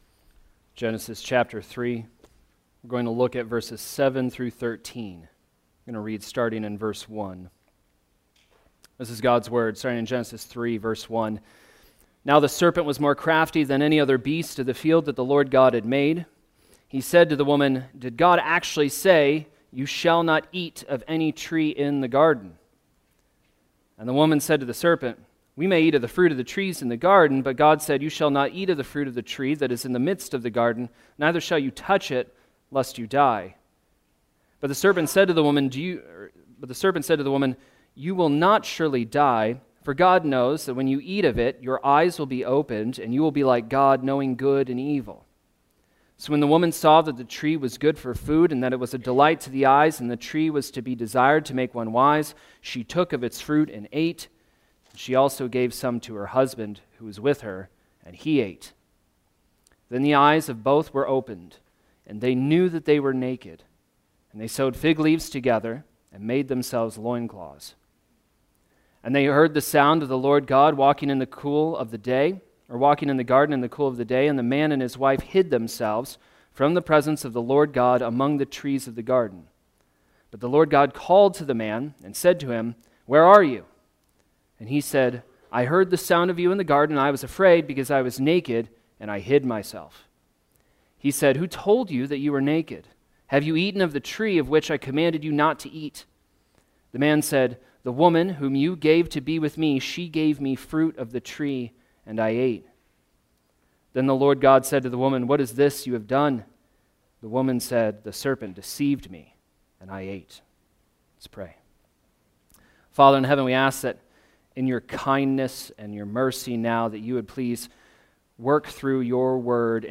Genesis Sermons